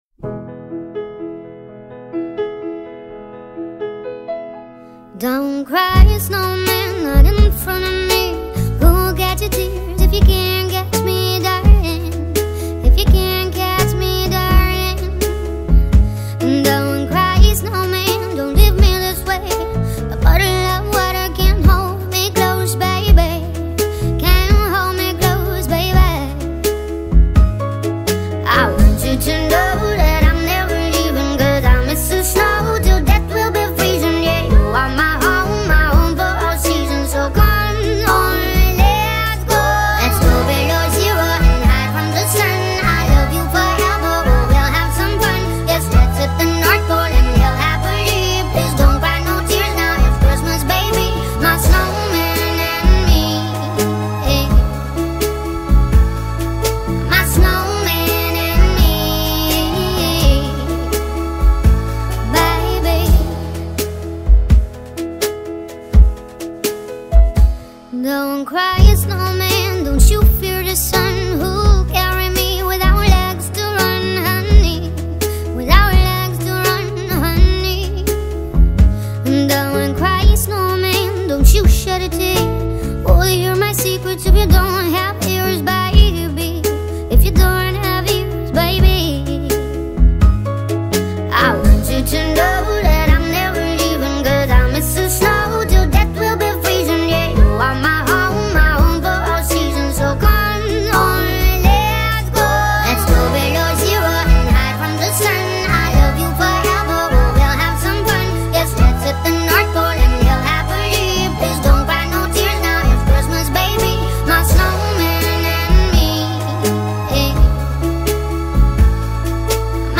در نسخه سریع شده و Sped Up